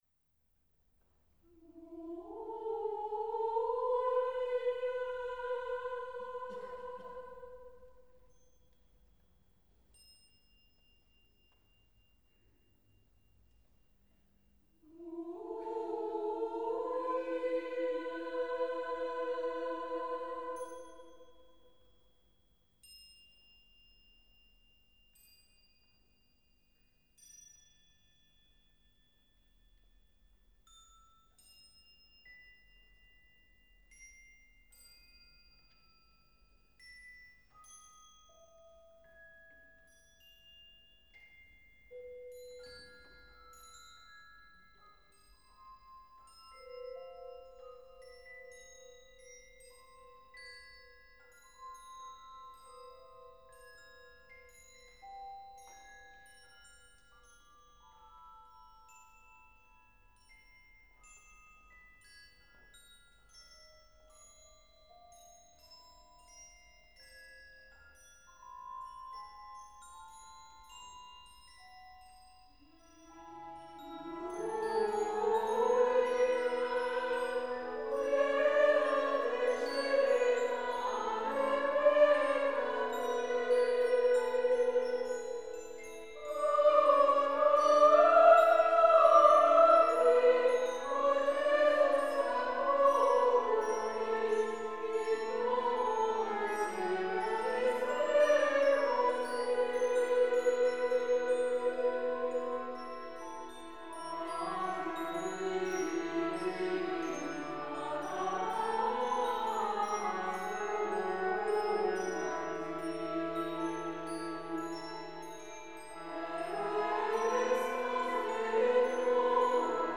3 parts women voices choir + orchestra